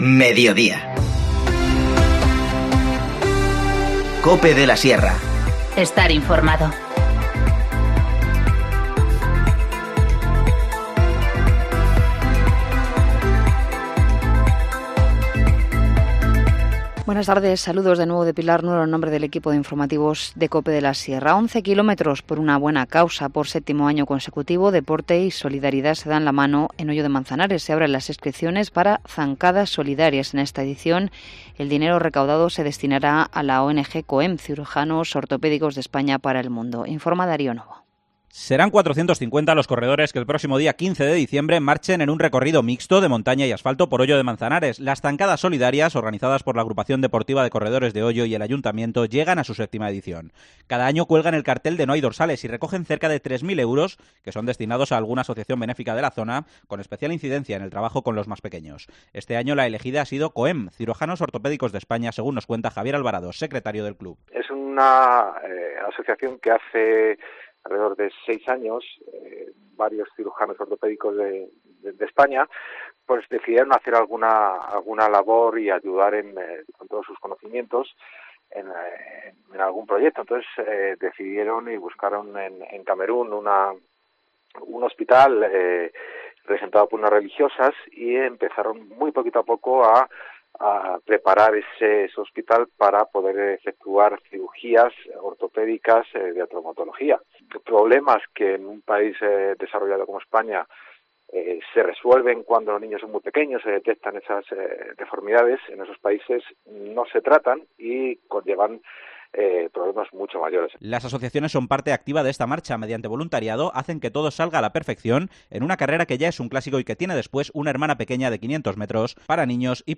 INFORMACIÓN LOCAL